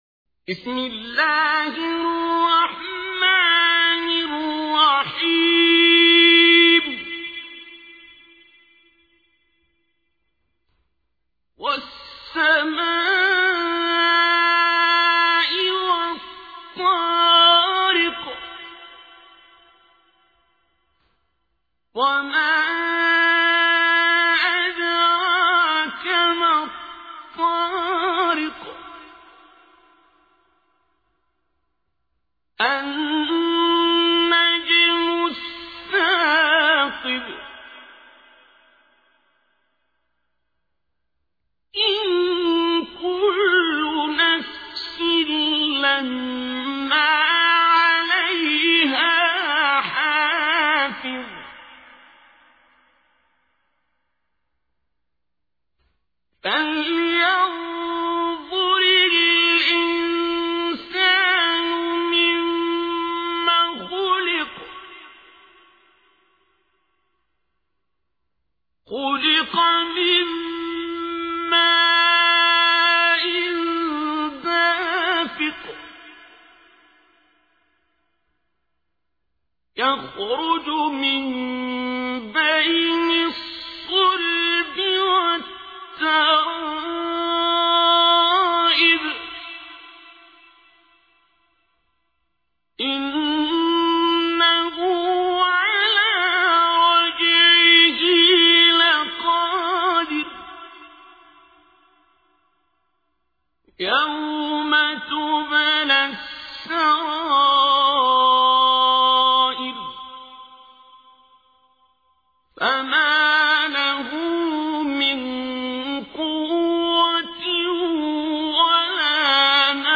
تحميل : 86. سورة الطارق / القارئ عبد الباسط عبد الصمد / القرآن الكريم / موقع يا حسين